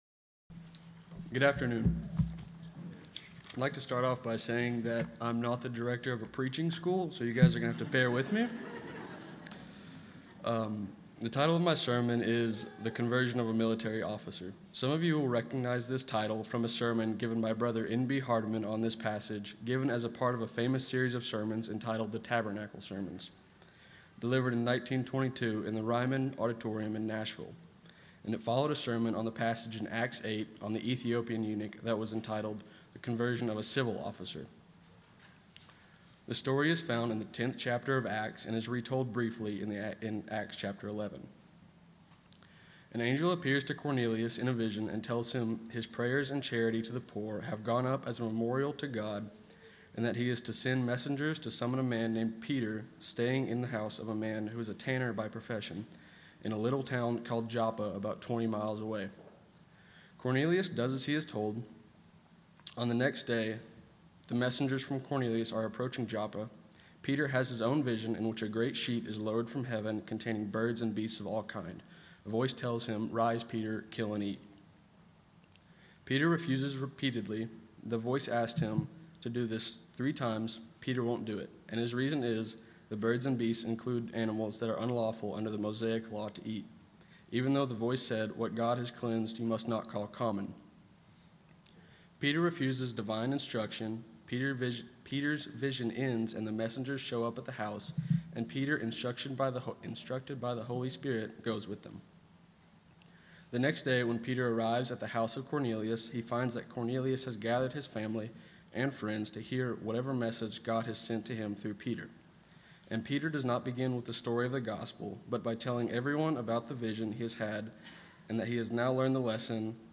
NB Hardeman Tabernacle Sermon